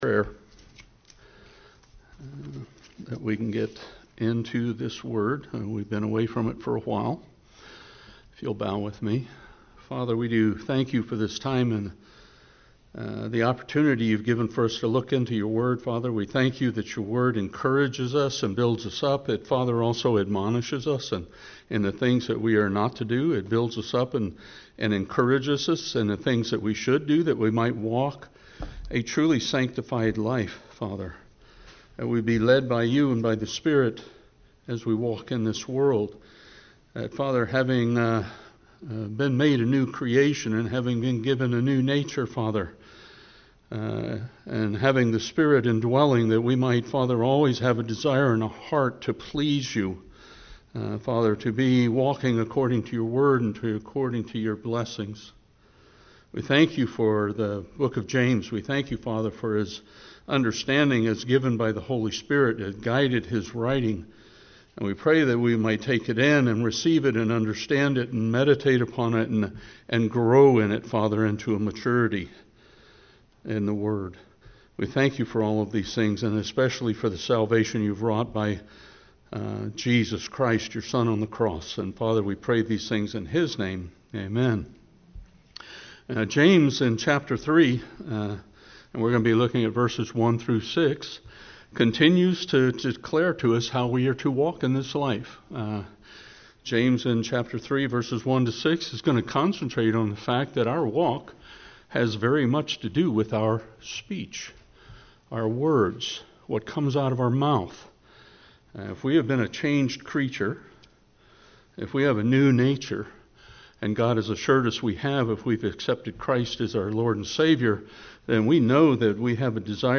Service Type: Bible Study